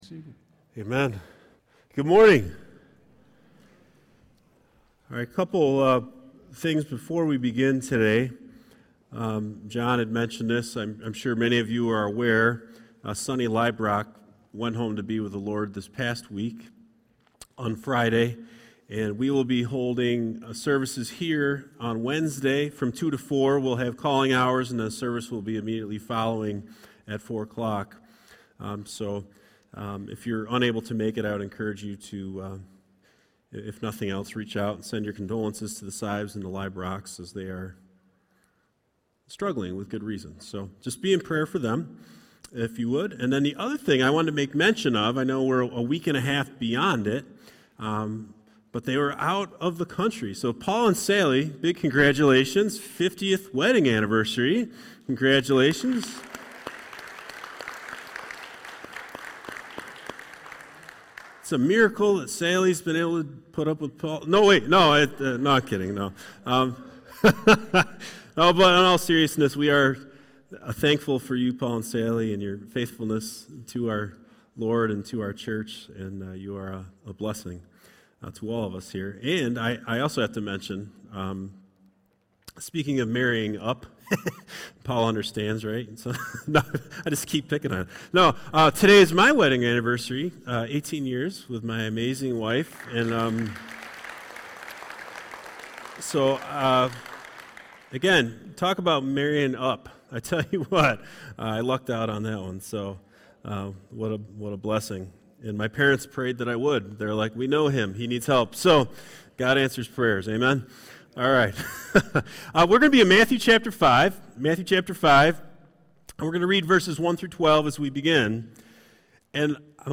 New sermon series on The Beatitudes this summer at Hartland Bible. The Sermon on the Mount is widely accepted as one of the greatest speeches of all time.